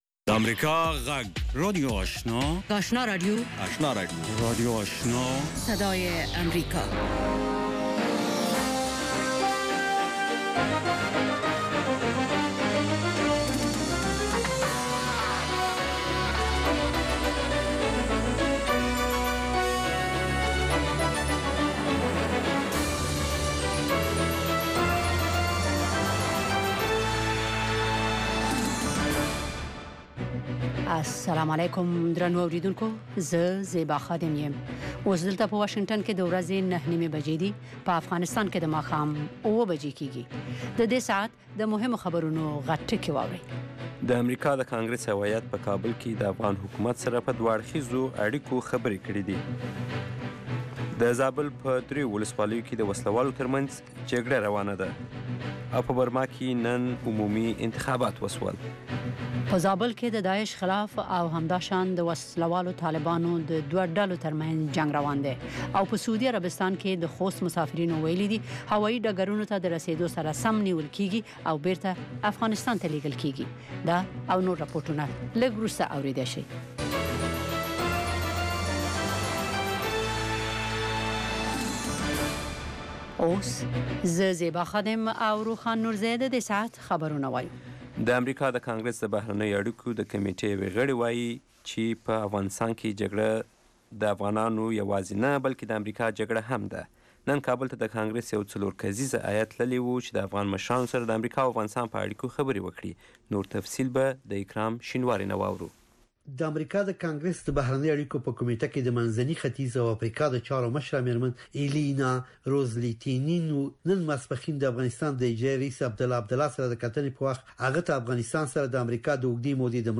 ماښامنۍ خبري خپرونه